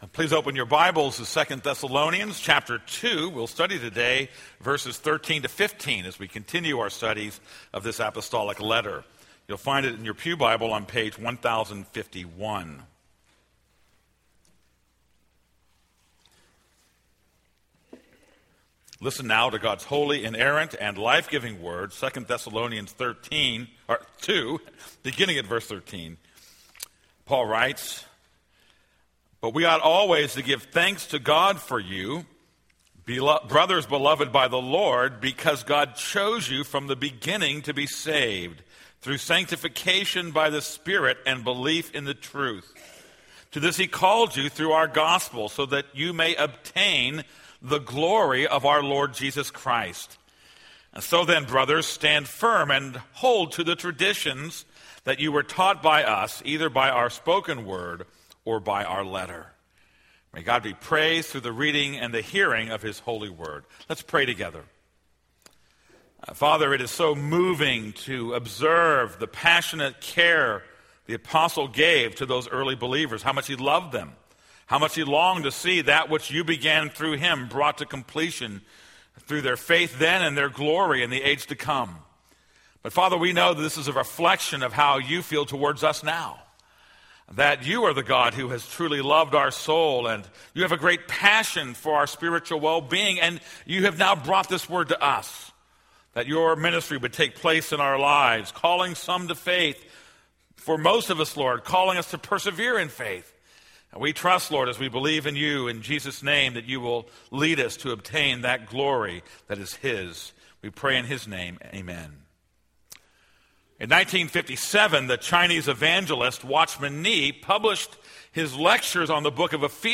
This is a sermon on 2 Thessalonians 2:13-15.